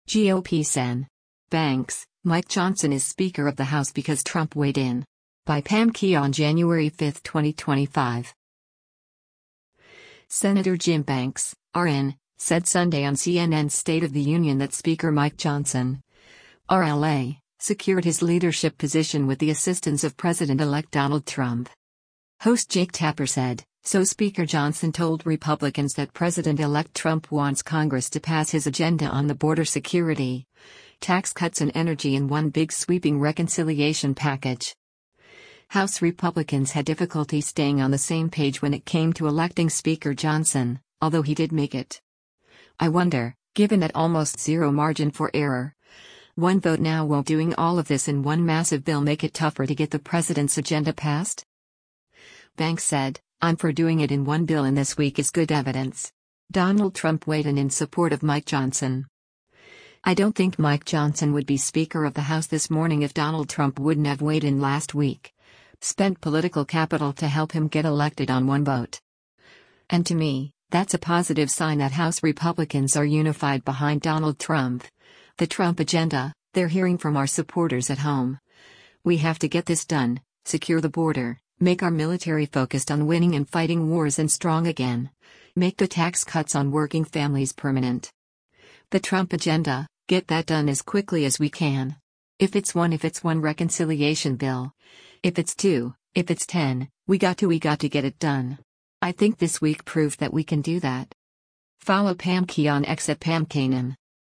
Senator Jim Banks (R-IN) said Sunday on CNN’s “State of the Union” that Speaker Mike Johnson (R-LA) secured his leadership position with the assistance of President-elect Donald Trump.